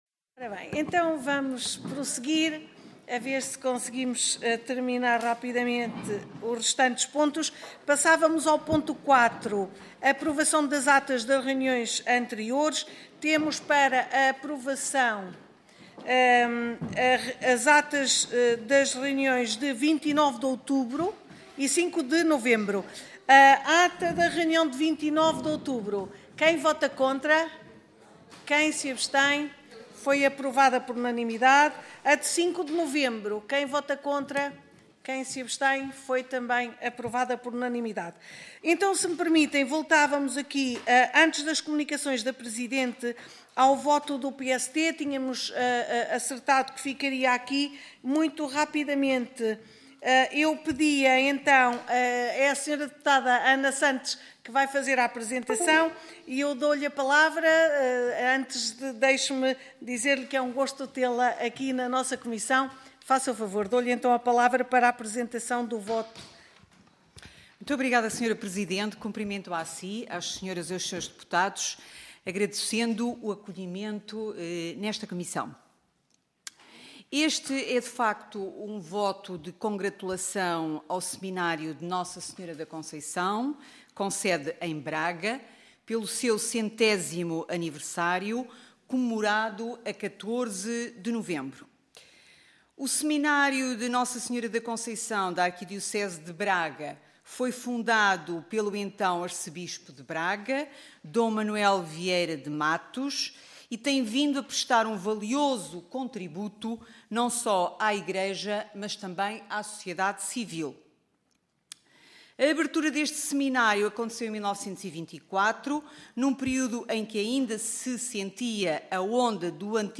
Gravação audio da discussão e votação